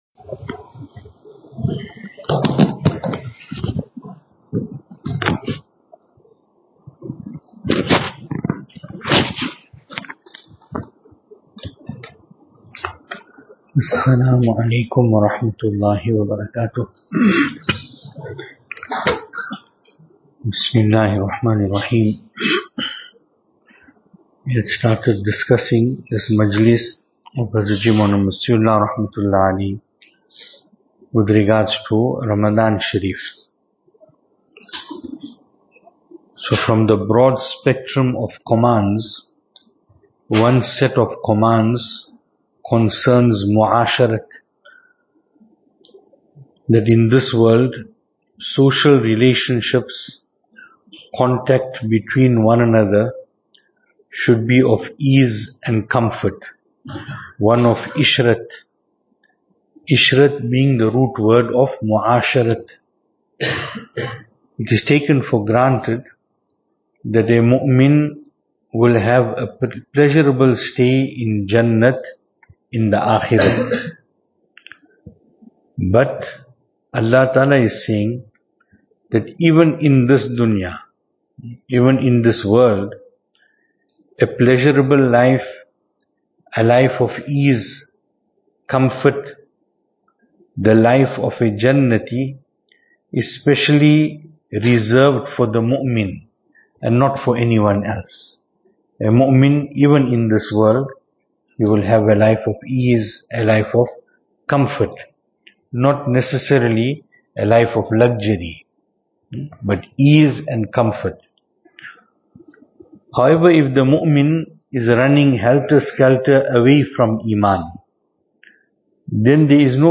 2026-03-30 Islahi Majlis Venue: Albert Falls , Madressa Isha'atul Haq Series: Islahi Majlis Service Type: Islahi Majlis Summary: ▪ By obeying Allah Ta’ala, a believer will experience a life of ease and comfort in this world.